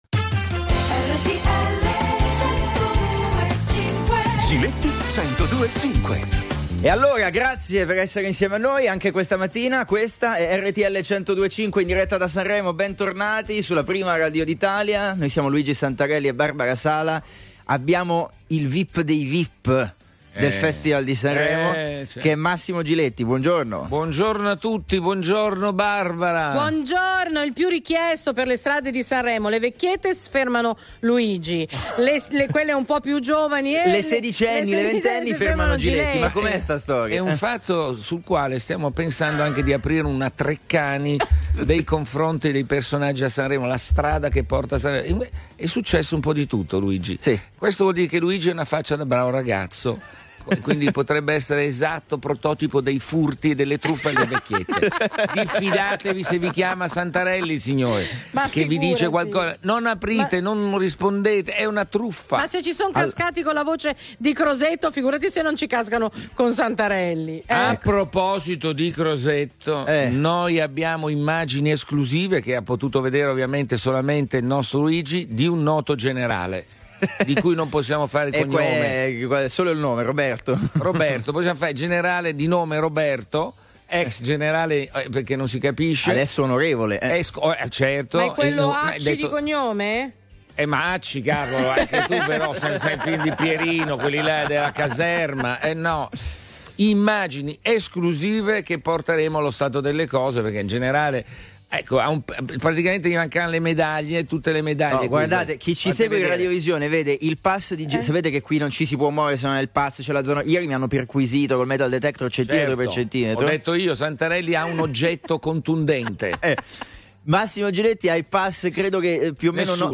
Telefoni aperti ai Very Normal People sui fatti della settimana.